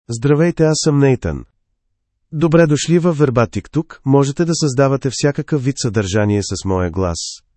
NathanMale Bulgarian AI voice
Nathan is a male AI voice for Bulgarian (Bulgaria).
Voice sample
Listen to Nathan's male Bulgarian voice.
Male
Nathan delivers clear pronunciation with authentic Bulgaria Bulgarian intonation, making your content sound professionally produced.